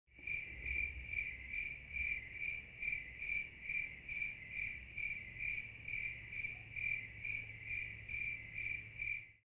Crickets 1